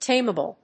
音節tame・a・ble 発音記号・読み方
/téɪməbl(米国英語)/